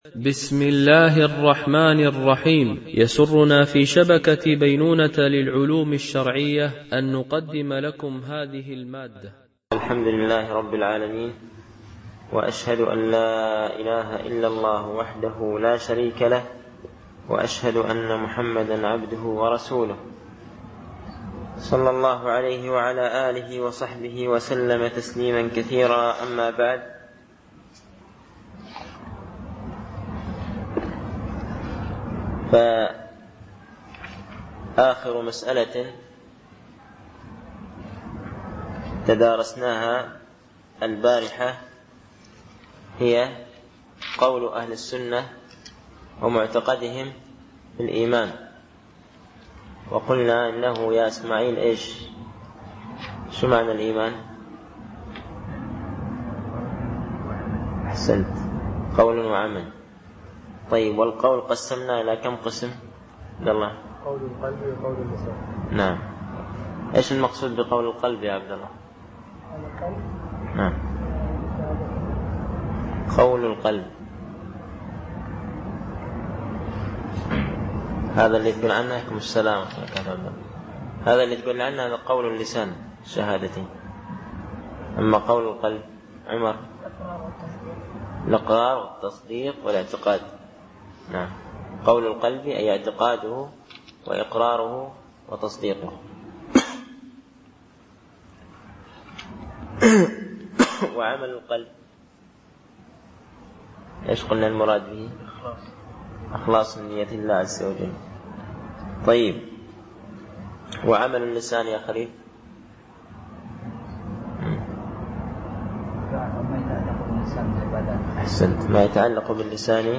) الألبوم: شبكة بينونة للعلوم الشرعية التتبع: 8 المدة: 43:04 دقائق (9.89 م.بايت) التنسيق: MP3 Mono 22kHz 32Kbps (CBR)